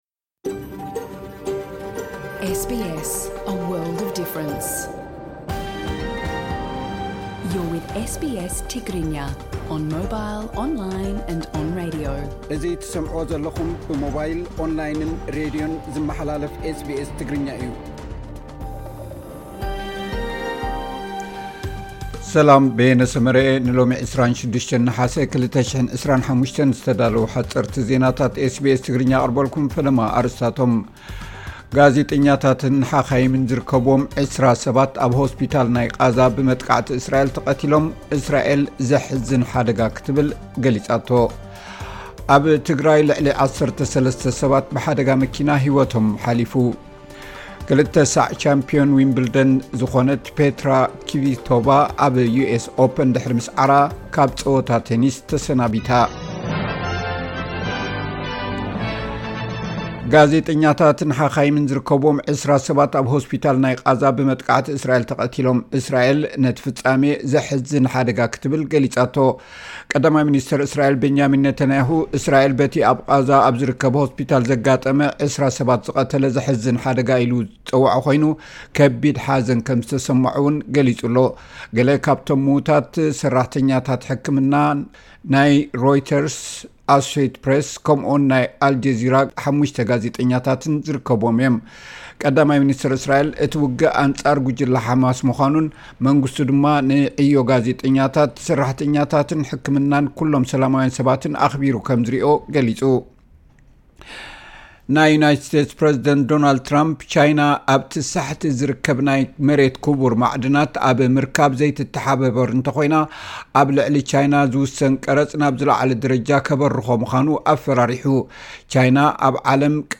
ሓጸርቲ ዜናታት ኤስ ቢ ኤስ ትግርኛ (26 ነሓሰ 2025)